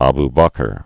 b-bäkər) also A·bu Bekr b bĕkər) 573?-634.